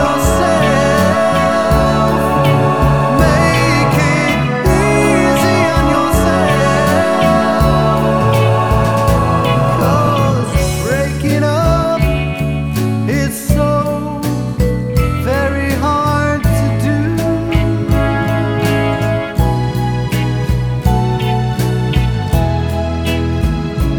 For Solo Male Pop (1960s) 3:13 Buy £1.50